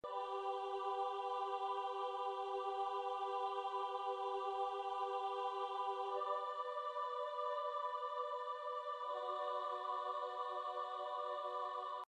Mein Nord Electro steuert einen schönen Aaah-Chor bei: